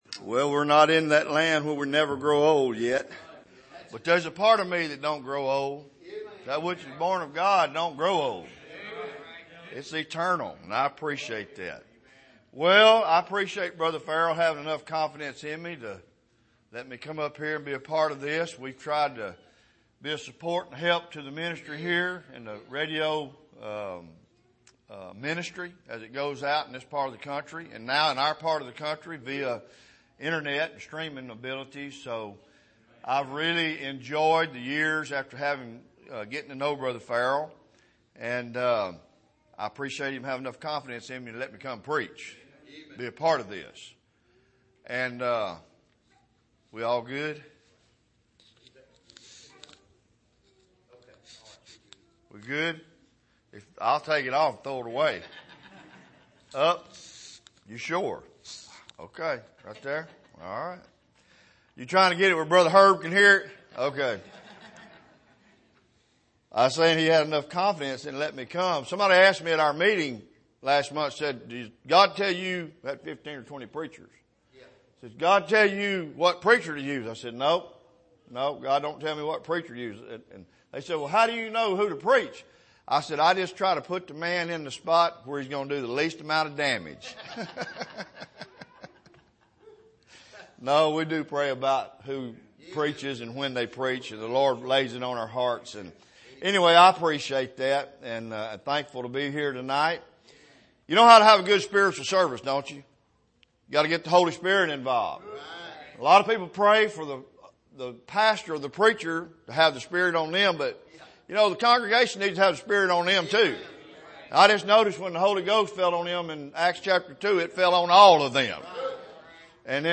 Here is an archive of messages preached at the Island Ford Baptist Church.